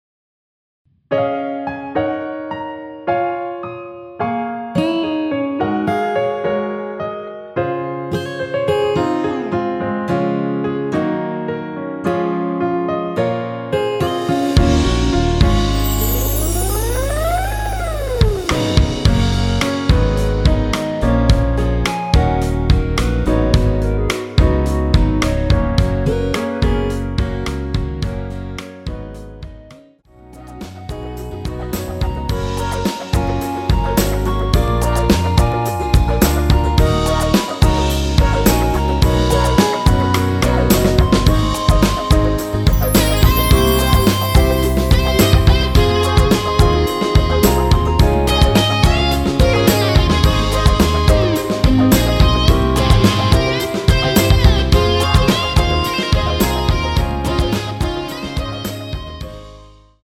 원키에서(-1)내린 멜로디 포함된 MR입니다.
Gb
앞부분30초, 뒷부분30초씩 편집해서 올려 드리고 있습니다.
(멜로디 MR)은 가이드 멜로디가 포함된 MR 입니다.